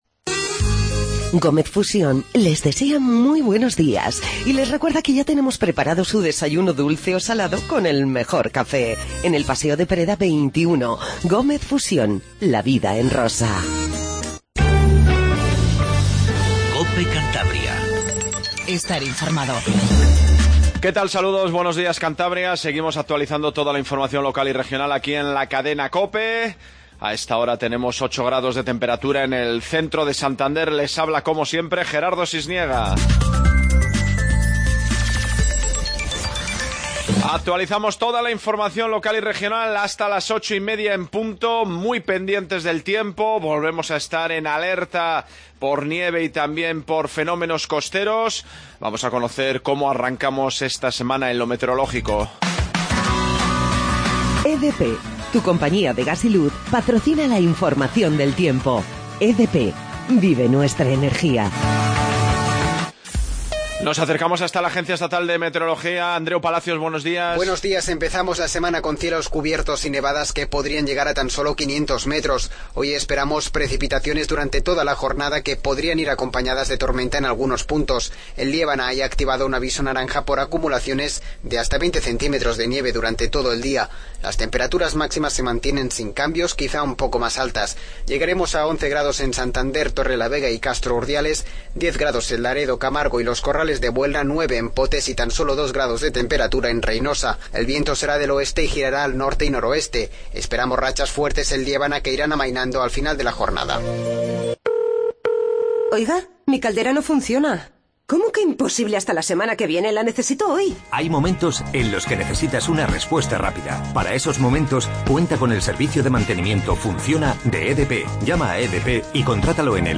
INFORMATIVO MATINAL 08:20